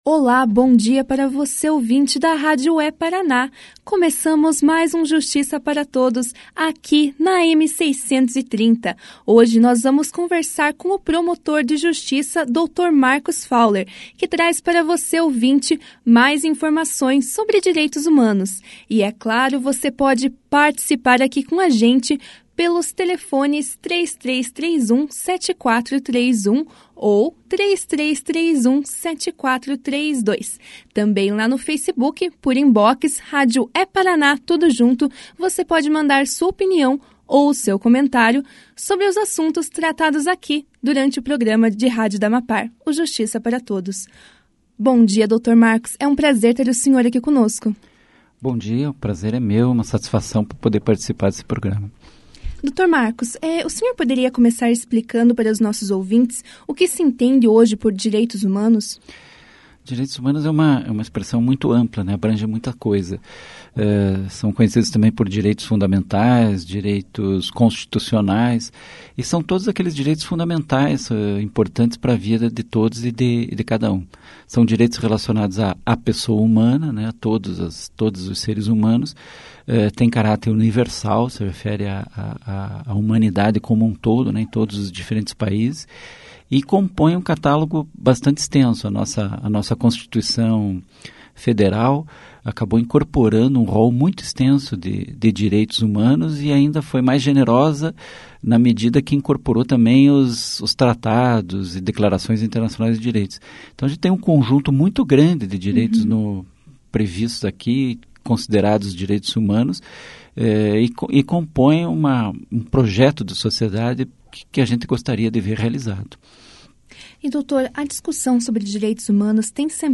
No programa de rádio da AMAPAR de hoje (22) o procurador de Justiça, Marcos Fowler, falou sobre Direitos Humanos. Fowler explicou sobre a recente ampliação da discussão desse tema nas redes sociais. Durante a entrevista o procurador também explicou como funciona o Centro de Apoio Operacional às Promotorias de Justiça de Proteção aos Direitos Humanos. Aproveitando o assunto, Fowler comentou a respeito dos direitos dos imigrantes.